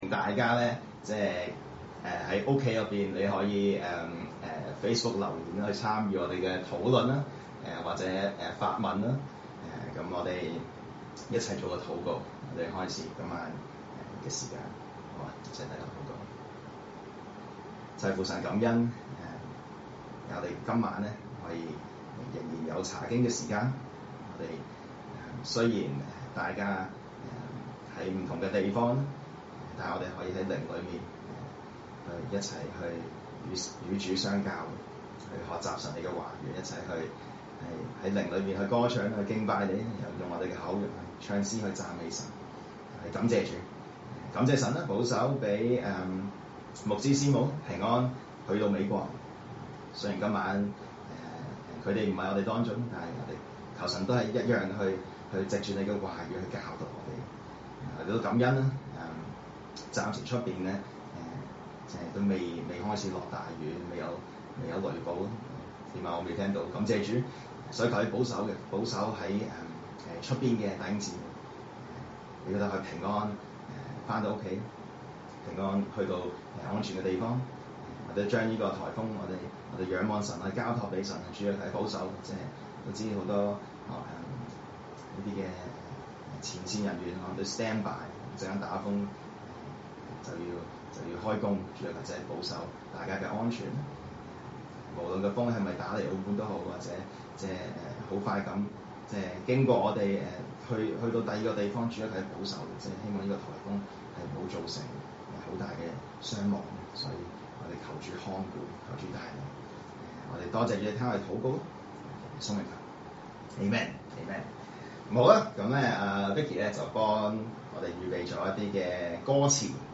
證道信息